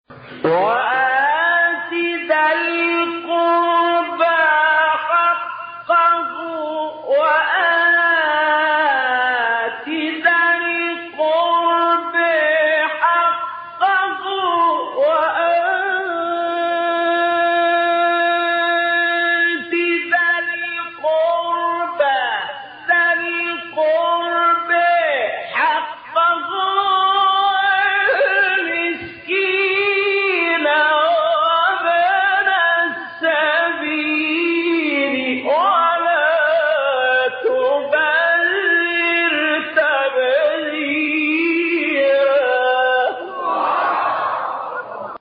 گروه شبکه اجتماعی: مقاطعی صوتی با صوت محمد اللیثی را که در مقام‌های مختلف اجرا شده است، می‌شنوید.
به گزارش خبرگزاری بین المللی قرآن(ایکنا) پنج فراز صوتی از سوره مبارکه اسراء با صوت محمد اللیثی، قاری برجسته مصری در کانال تلگرامی قاریان مصری منتشر شده است.
مقام بیات